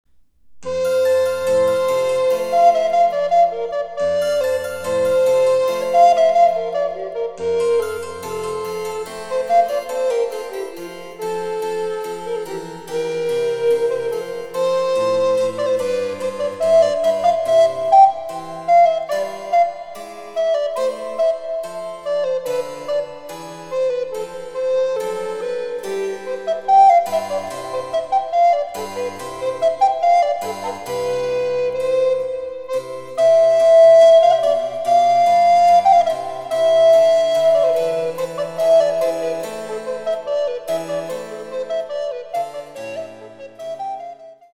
リコーダー演奏